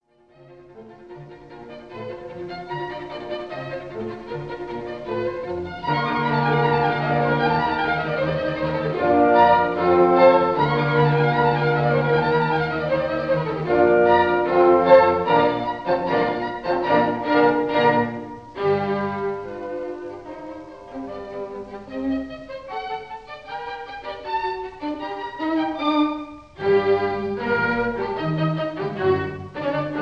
Ochestral Suite from